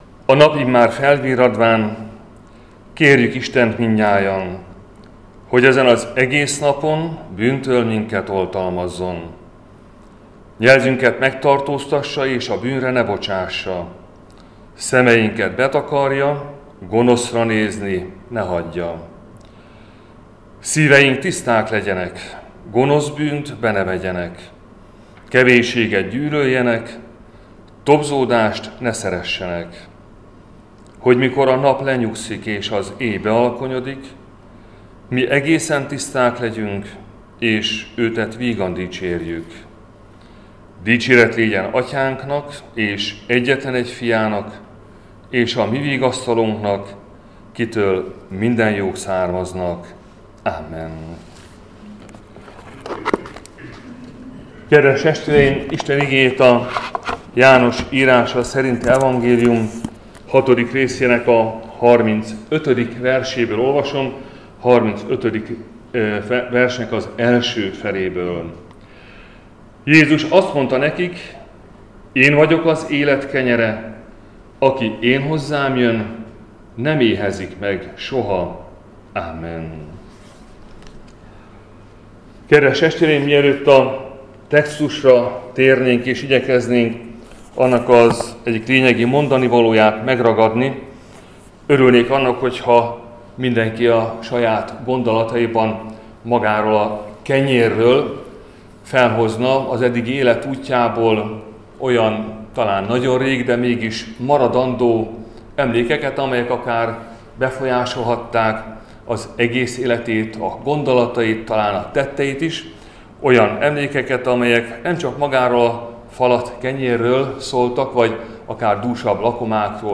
Áhítat, 2026. január 20.
Áhítatok a püspöki hivatalban 2026. január 21.